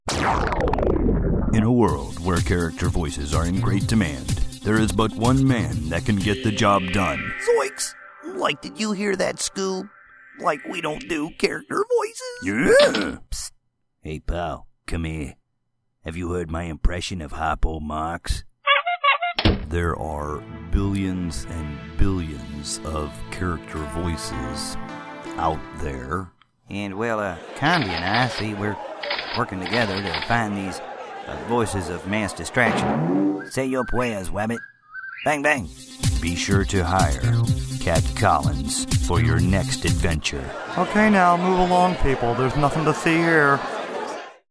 Voice actor and character voice actor for TV, Radio, Online ads, Podcasts, Video Games, etc.
Character Demo
US Mid-Atlantic, US-Southern, US-Western, US-Mid-Western, British-General